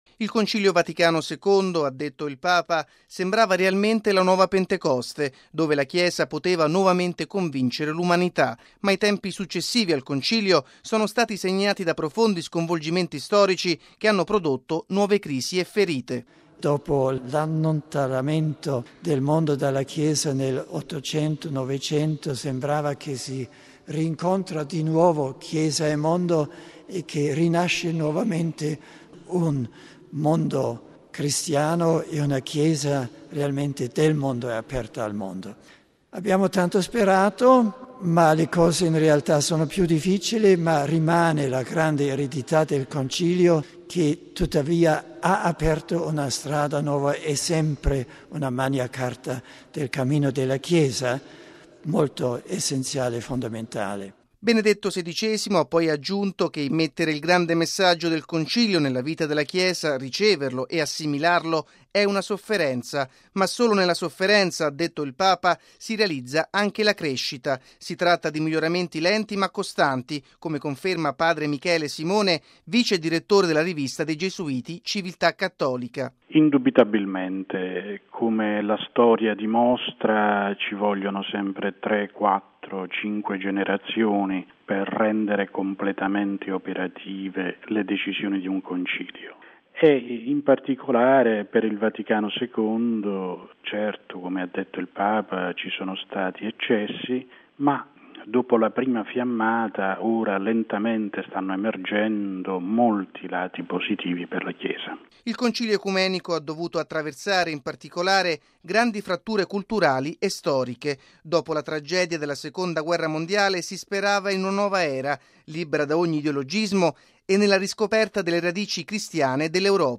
◊   L’attualità del Concilio Vaticano II è stato uno dei temi al centro dell’incontro, tenutosi martedì scorso nella chiesa di Santa Giustina Martire ad Auronzo di Cadore, tra Benedetto XVI ed il clero delle diocesi di Treviso e Belluno-Feltre.